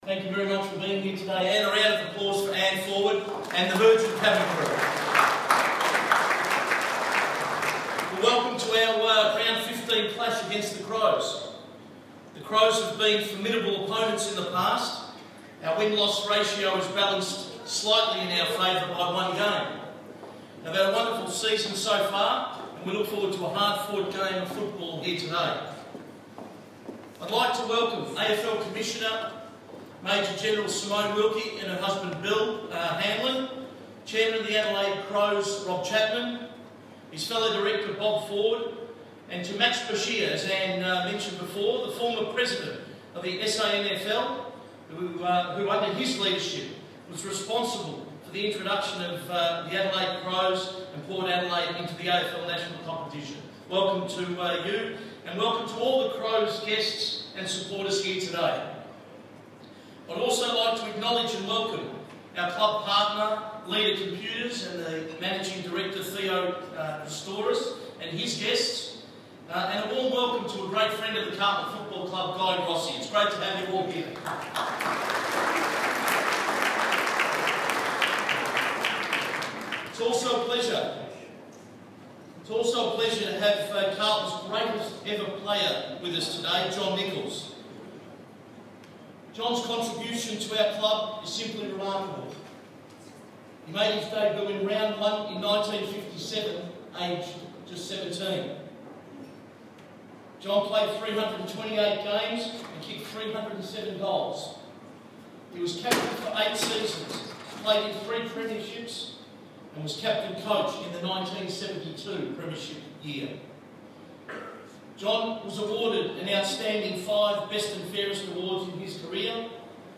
addresses the crowd at the Round 15 Carlton President’s Club by Virgin Australia.